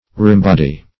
Reembody \Re`em*bod"y\ (r?`?m*b?d"?)